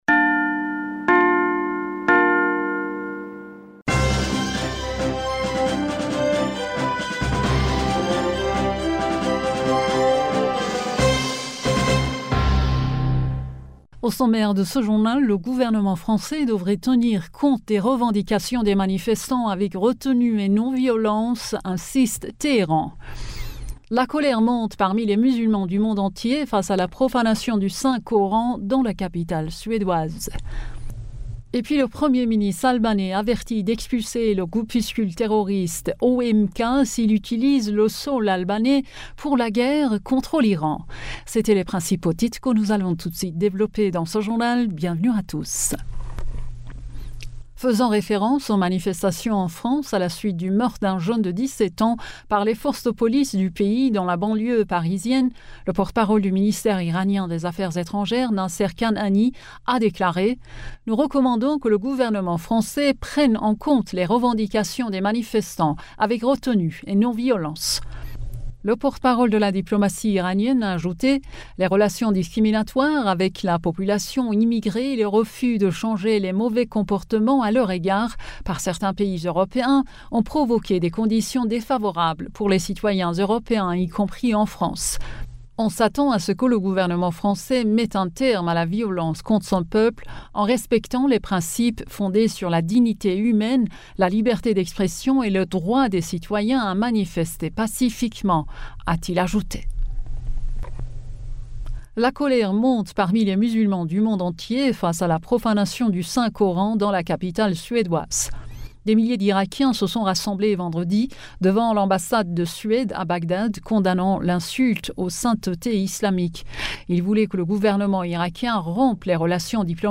Bulletin d'information du 02 Juillet 2023